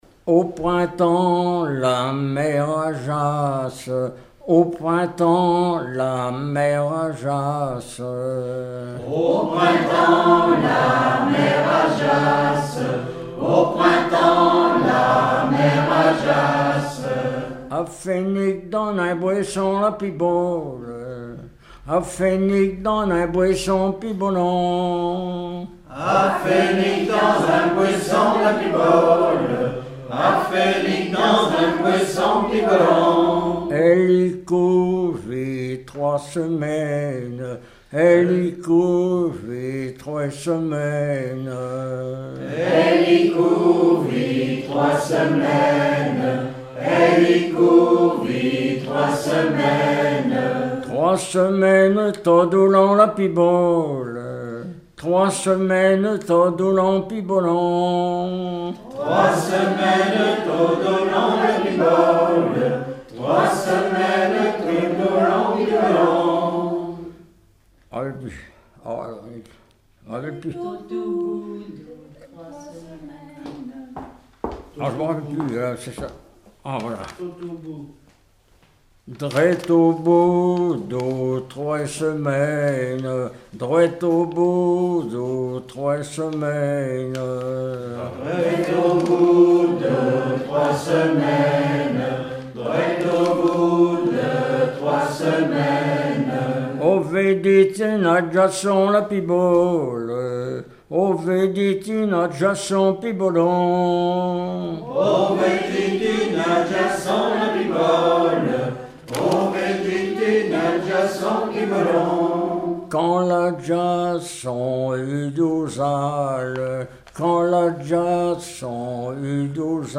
collectif de chanteurs du canton
Pièce musicale inédite